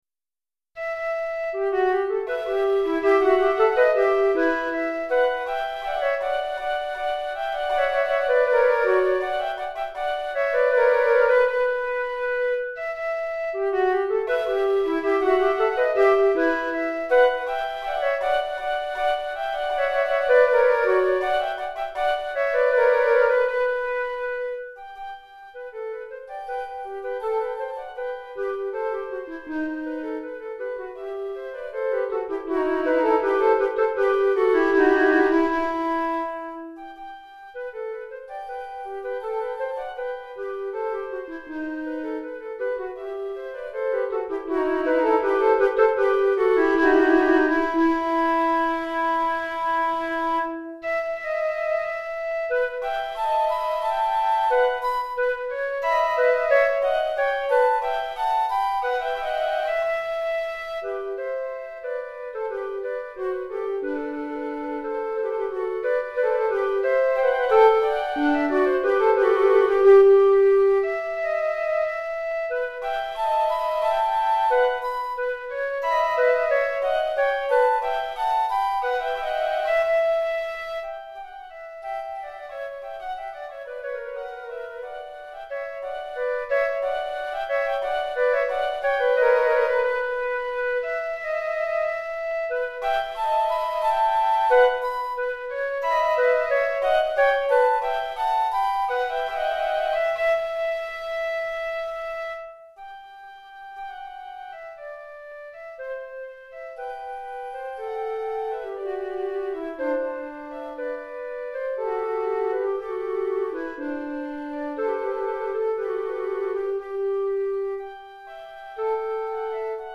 2 Flûtes Traversières